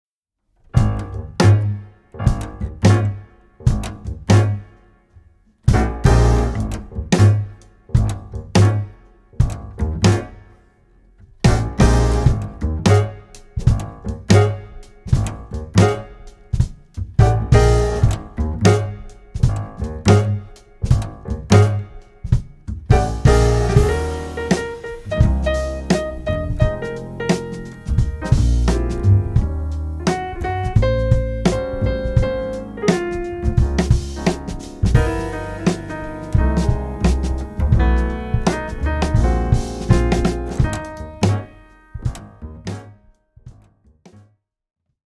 クールで情熱的なオリジナルアレンジがジャズの伝統と現代を融合。
深いグルーヴ、洗練された旋律、躍動感が織りなす極上のサウンド。